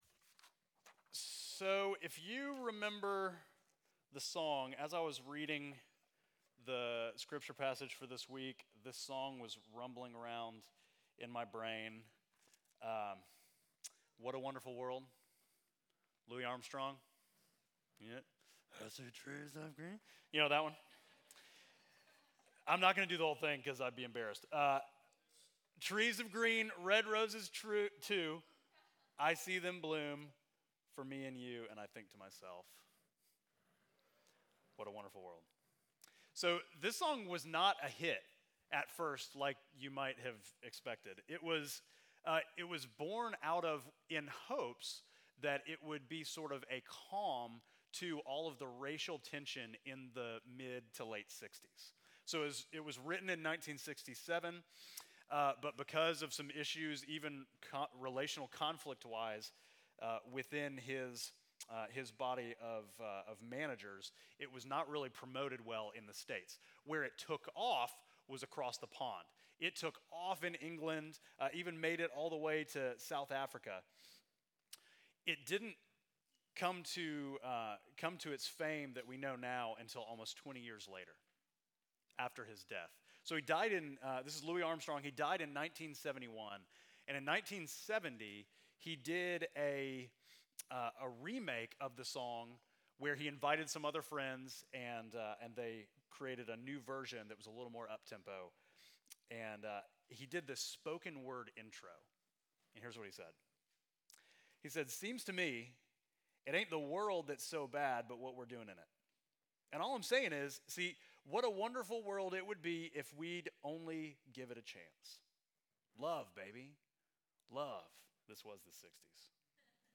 Midtown Fellowship Crieve Hall Sermons The Way of the King: Law Mar 10 2024 | 00:44:58 1x 00:00 / 00:44:58 Subscribe Share Apple Podcasts Spotify Overcast RSS Feed Share Link Embed